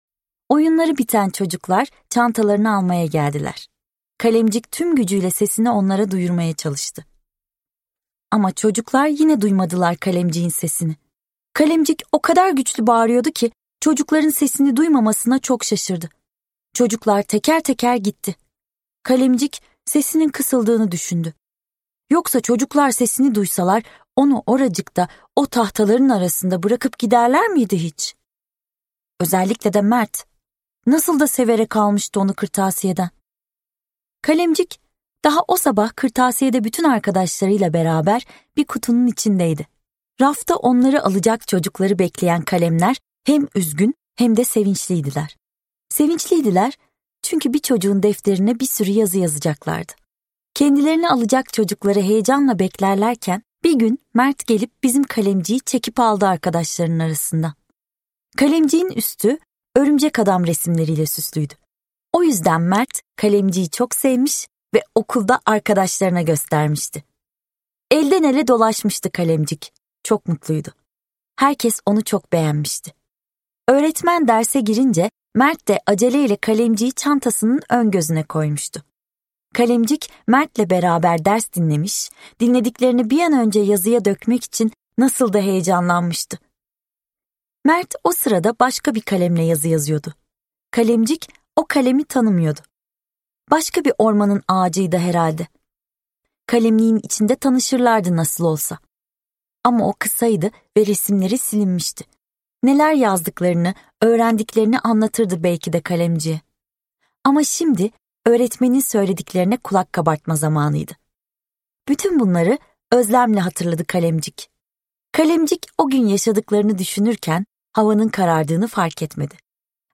Son Kurşunkalem - Seslenen Kitap